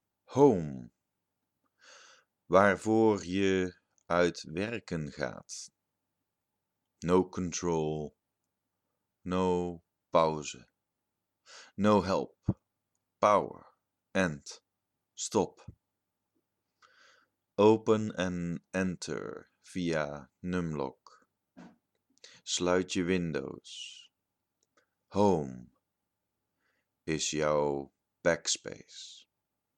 Poëzie